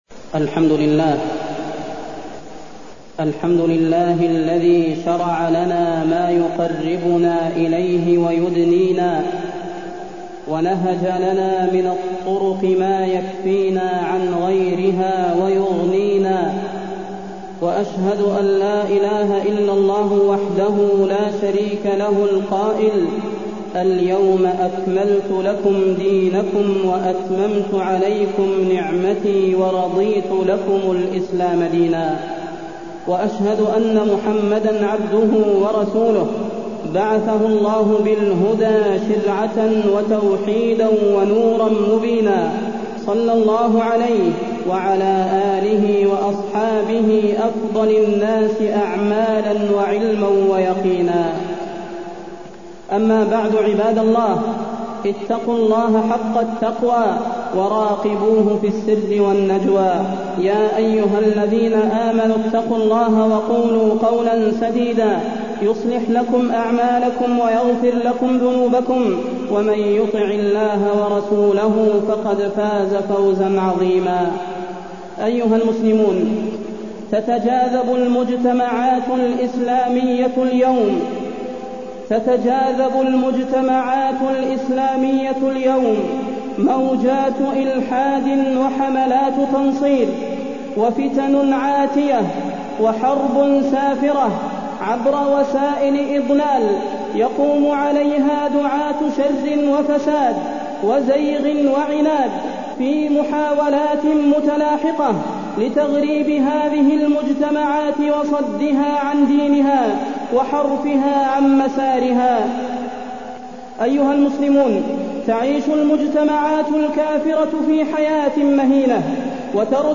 فضيلة الشيخ د. صلاح بن محمد البدير
تاريخ النشر ٤ رجب ١٤٢٢ هـ المكان: المسجد النبوي الشيخ: فضيلة الشيخ د. صلاح بن محمد البدير فضيلة الشيخ د. صلاح بن محمد البدير التمسك بالكتاب والسنة هو سبيل النجاة